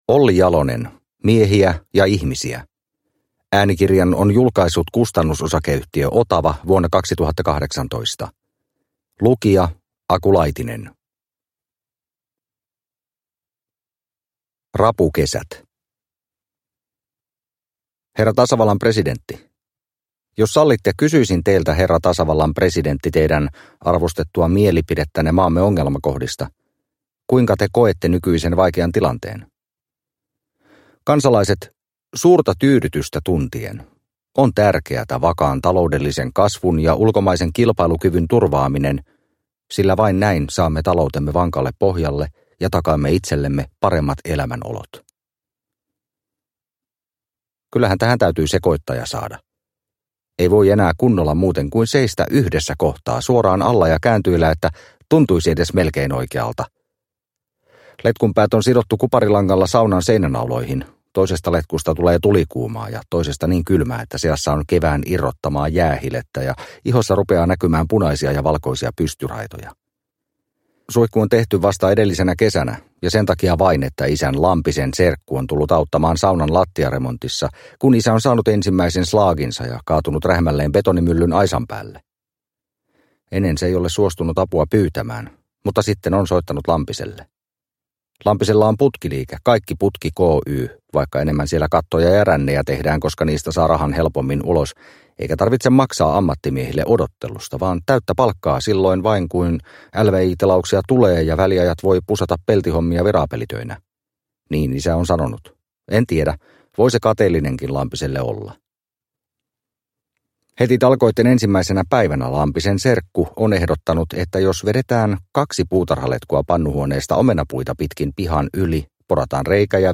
Miehiä ja ihmisiä – Ljudbok – Laddas ner